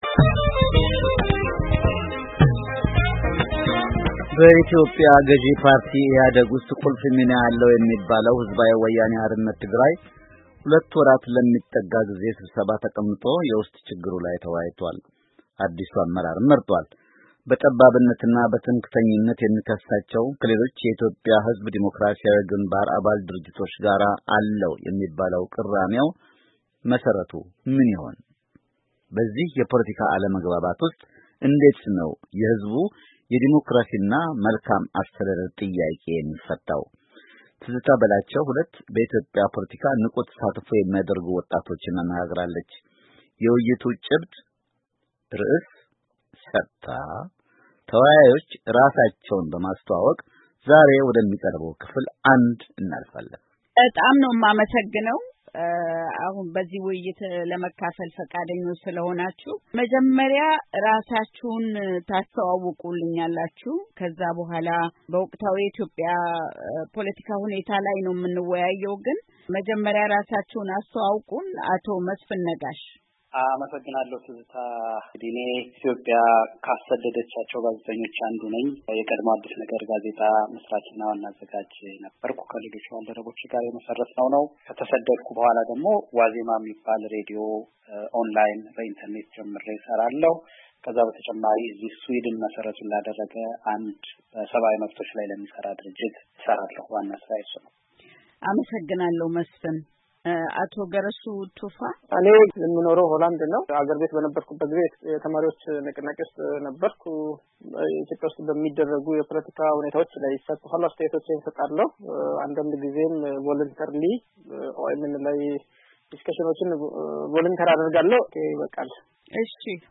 በኢትዮጵያ ወቅታዊ ፖለቲካ ላይ ውይይት